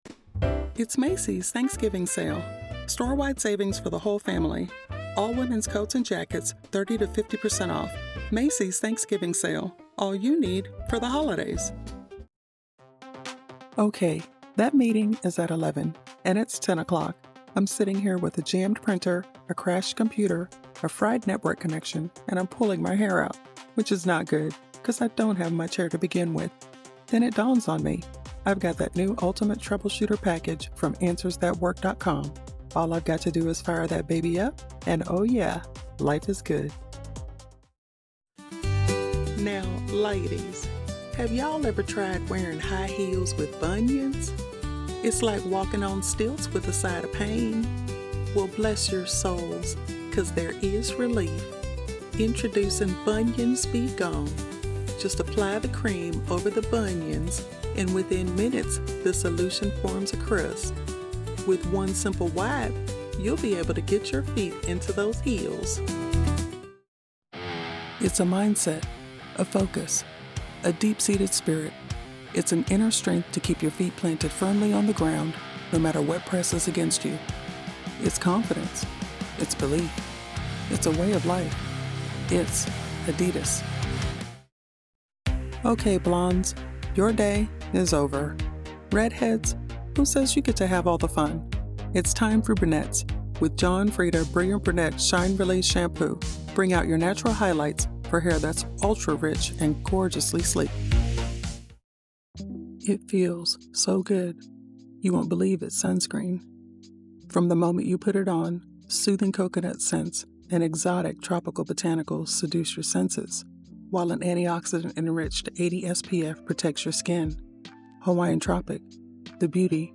0821Commercial_Demos_1.mp3